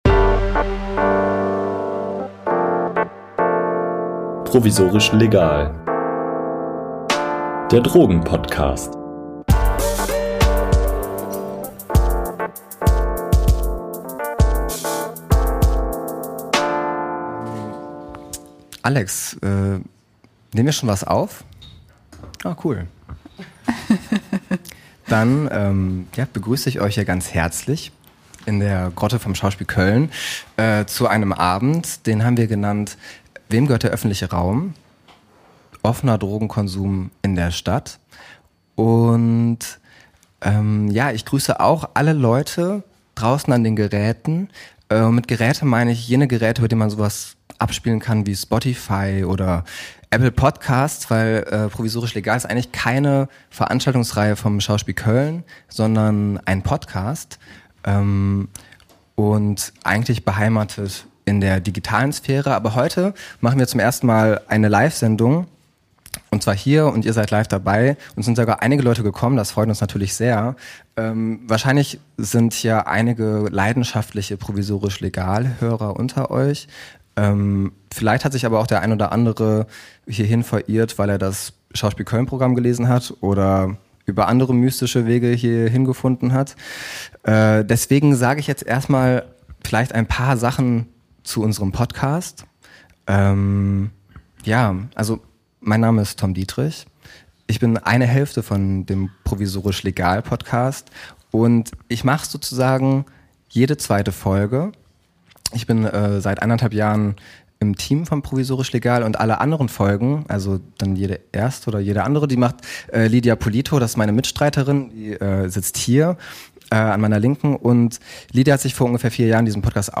Diese Folge wurde am 19.06.2025 live in der Grotte des Schauspiel Köln im Rahmen der Veranstaltungsreihe "Studiobüdchen" aufgenommen.